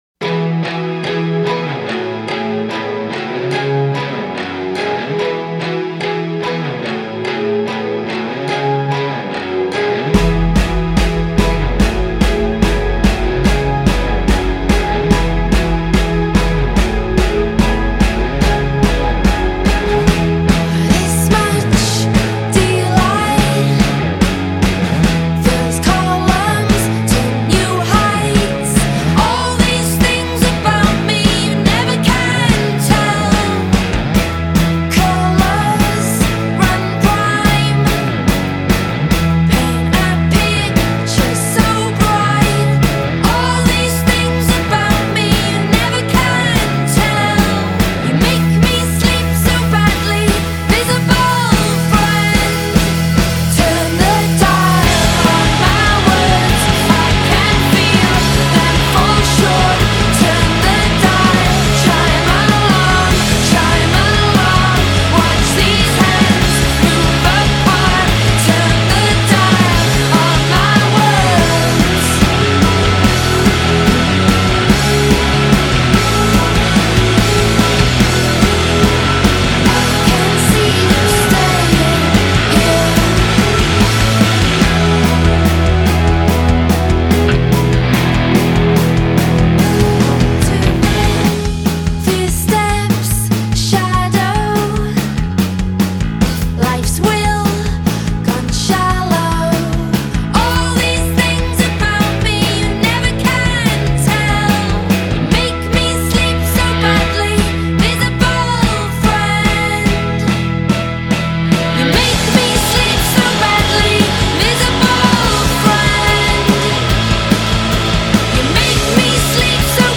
Welsh rockers